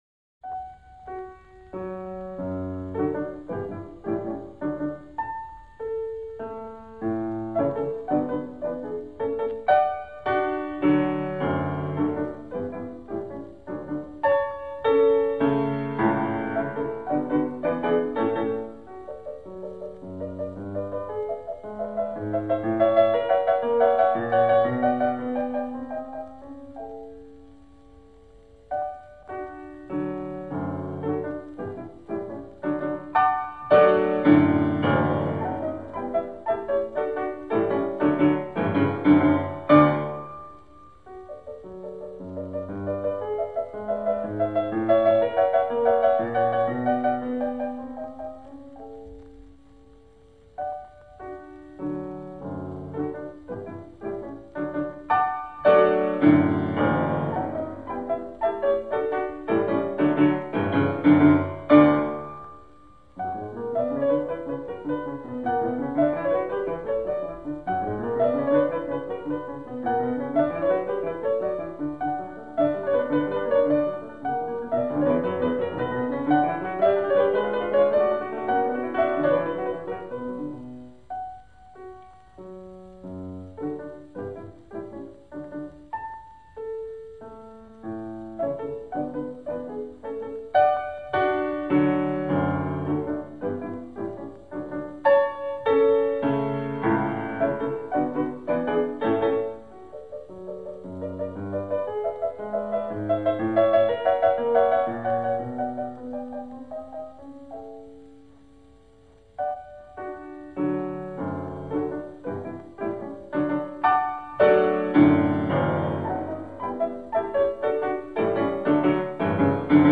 Scherzo (Allegro vivace) & Trio